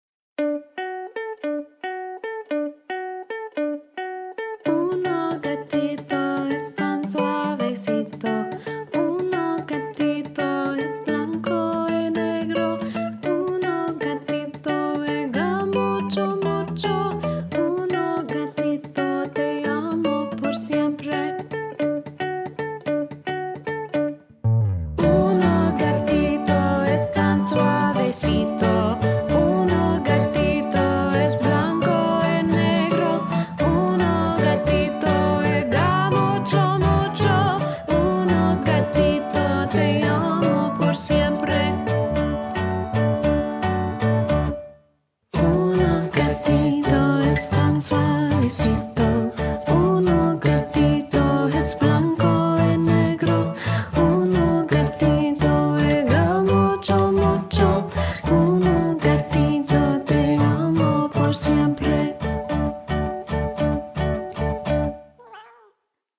This is essentially a children's song or even a jingle.
I don't think it sounds terribly muddy. It could maybe be a little leaner, but it didn't really bug me or anything.